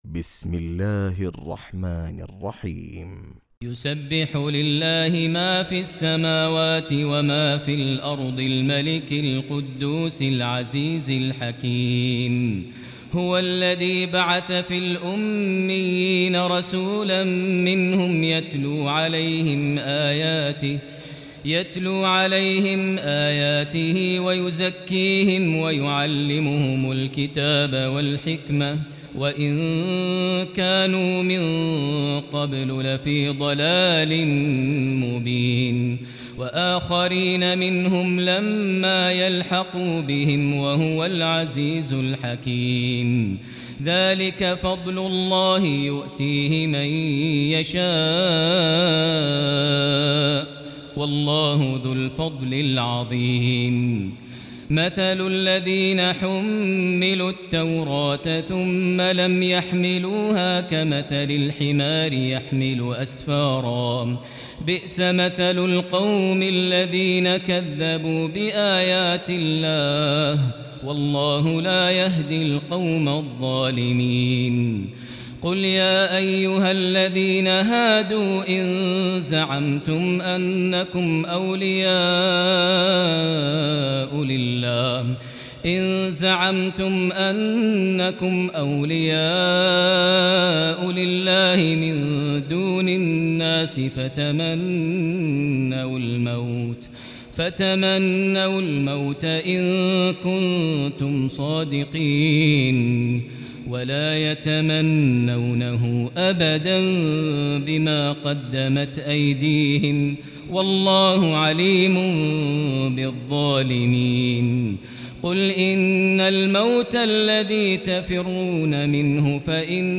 تراويح ليلة 27 رمضان 1439هـ من سورة الجمعة الى التحريم Taraweeh 27 st night Ramadan 1439H from Surah Al-Jumu'a to At-Tahrim > تراويح الحرم المكي عام 1439 🕋 > التراويح - تلاوات الحرمين